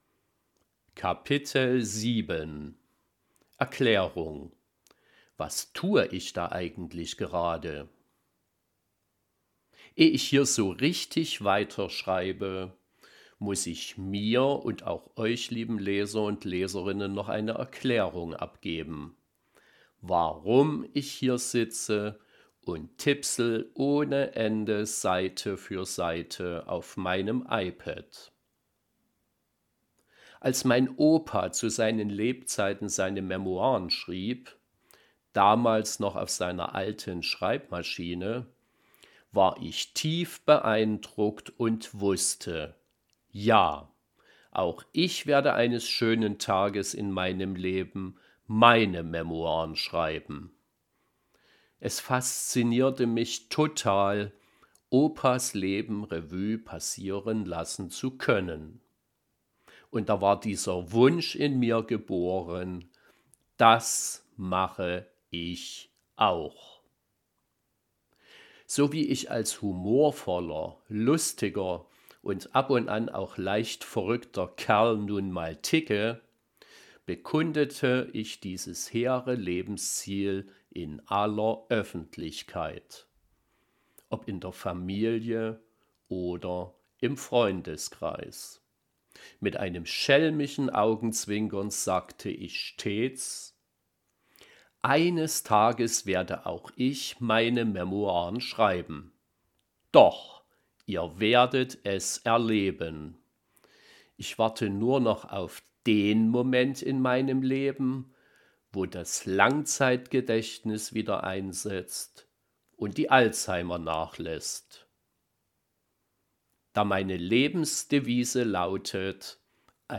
Dieses Hörbuch ist keine klassische Studio- Produktion.